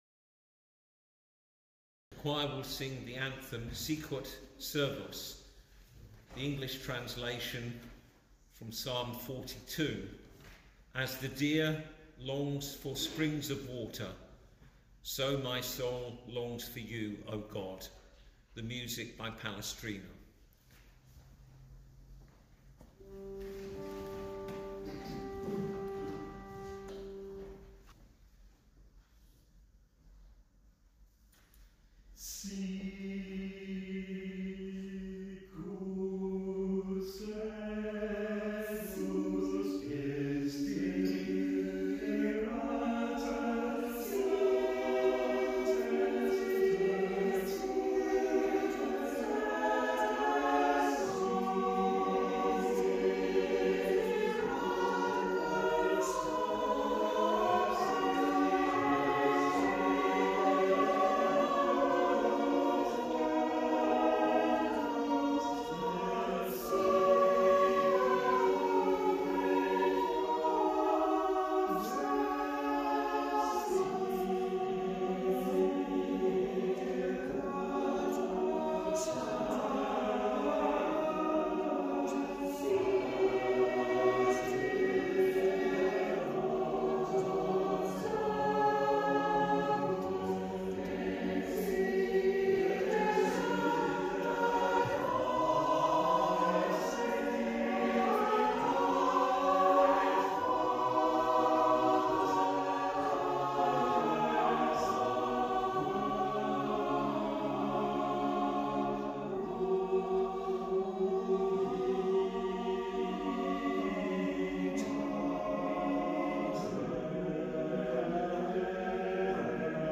Deanery Choir
Here is a list of recordings from the choir: